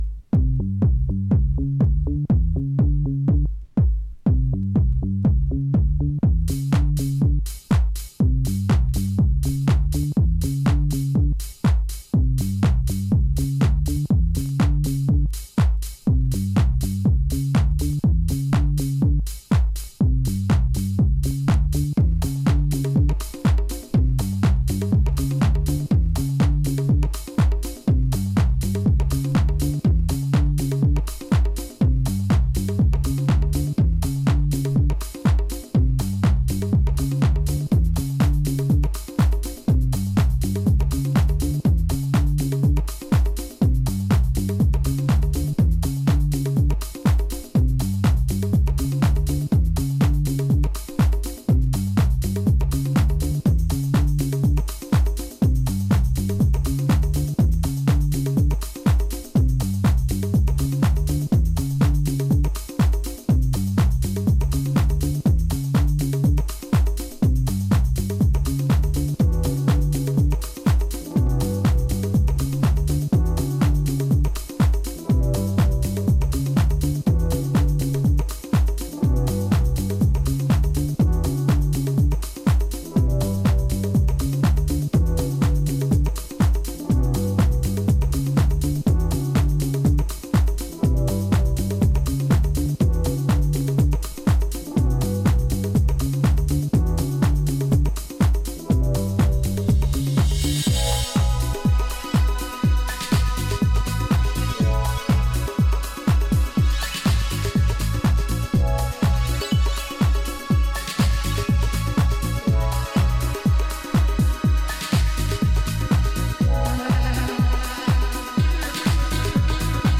cool and percussive
relaxed, sumptuously produced class